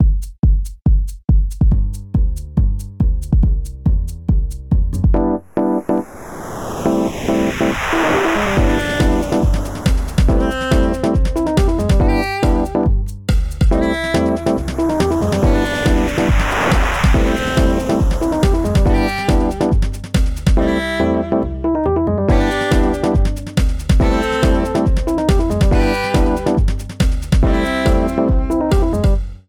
(0:29) Some happy jungle beat near an airport